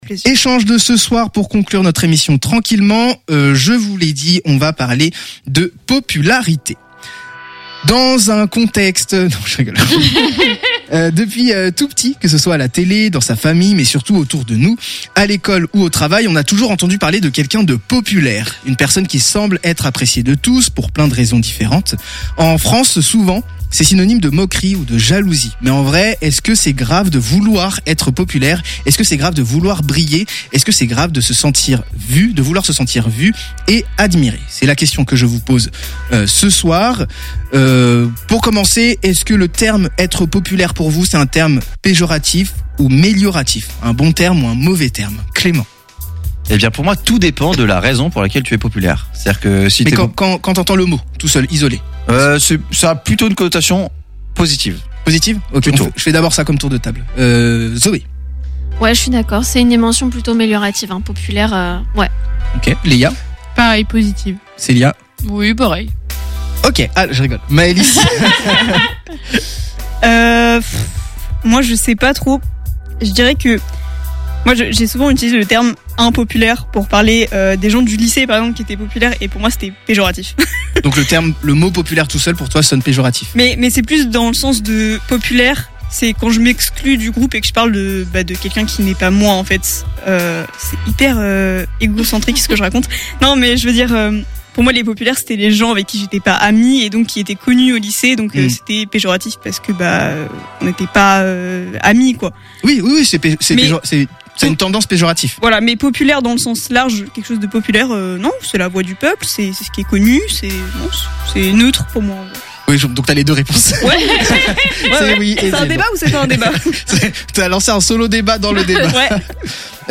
Débat - G!